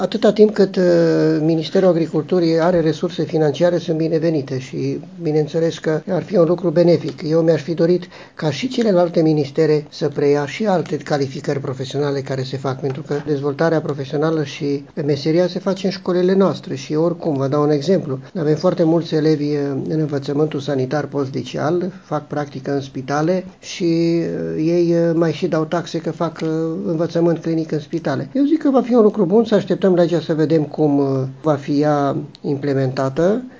Inspectorul școlar general al județului Mureș, Ioan Macarie, a salutat proiectul și susține că ar fi util ca modelul să se aplice și liceelor de alt profil: